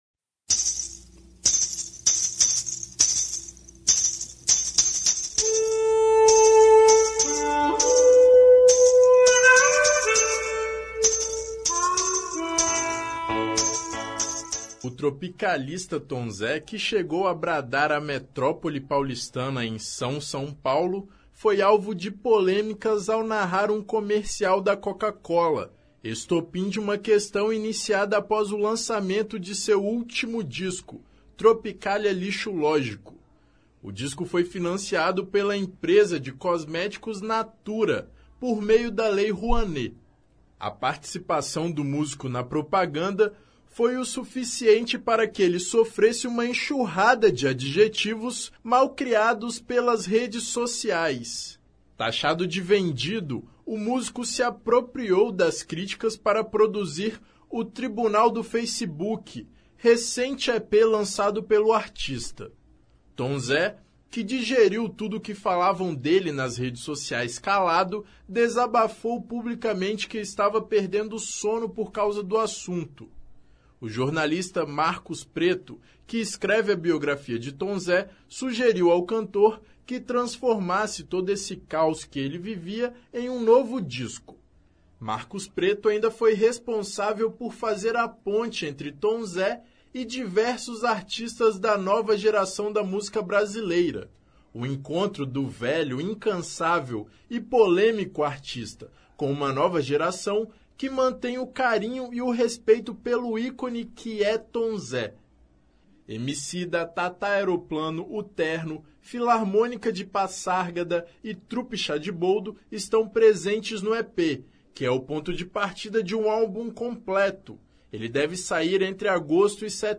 Tom Zé lançou, recentemente, uma música que é inspirada nas manifestações populares que tomaram o país, nas últimas semanas. Atento às redes sociais, o artista divulgou há pouco tempo um EP chamado “Tribunal do Feicebuqui” - disponível para ser baixado gratuitamente em seu site; a matéria que o Revista Universitária preparou também apresenta a música “Povo Novo”.